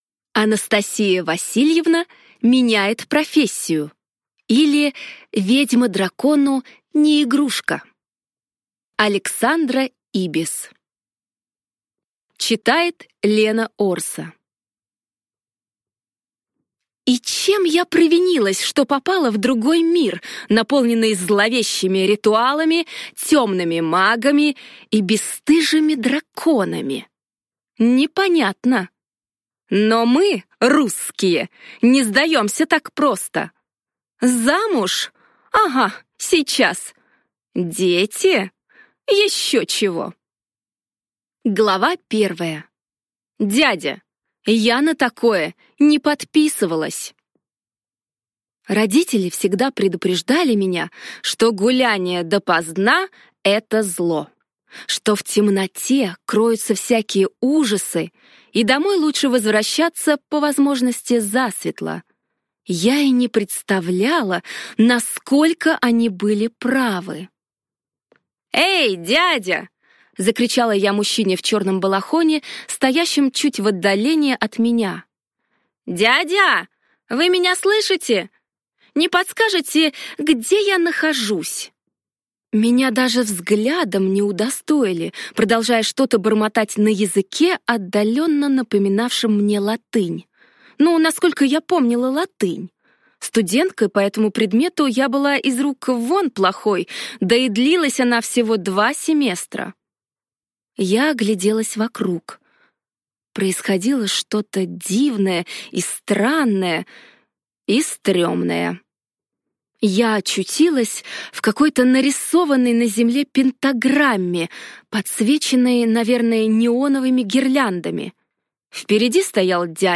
Аудиокнига Анастасия Васильевна меняет профессию, или ведьма дракону не игрушка | Библиотека аудиокниг